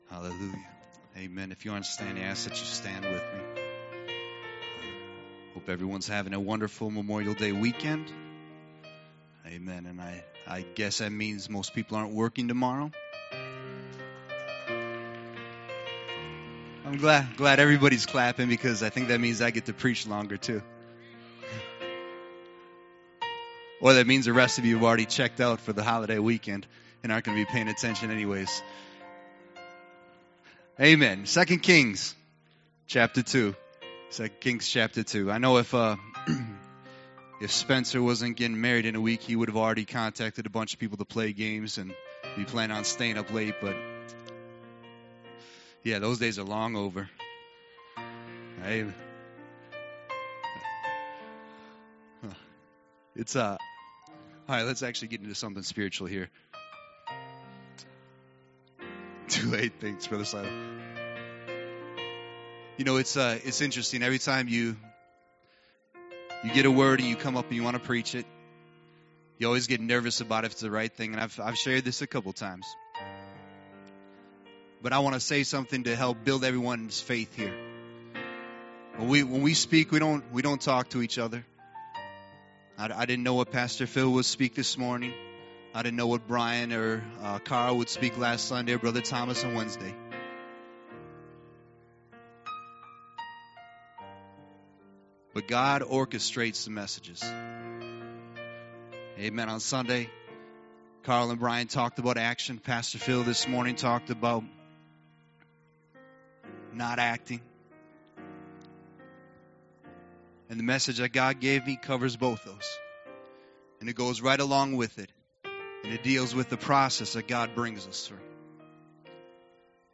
A message from the series "Calvary Gospel Church."